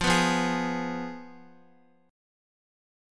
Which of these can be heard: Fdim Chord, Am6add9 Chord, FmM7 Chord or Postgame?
Fdim Chord